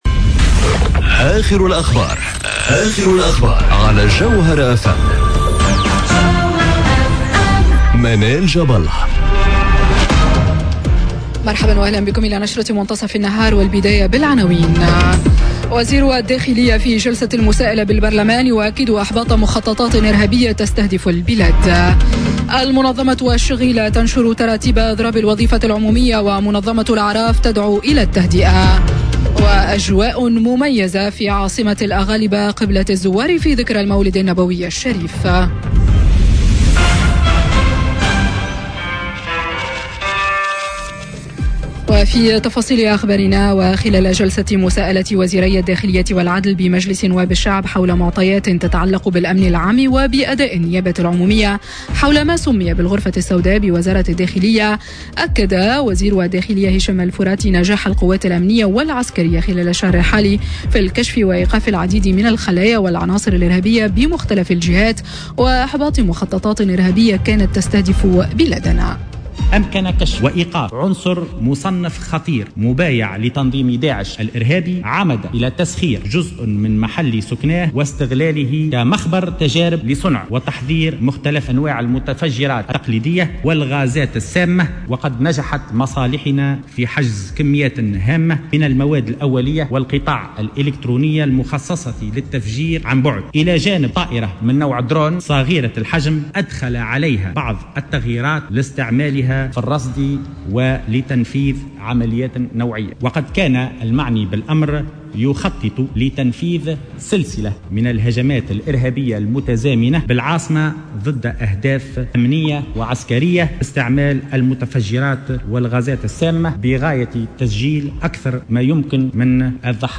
نشرة أخبار منتصف النهار ليوم الإثنين 19 نوفمبر 2018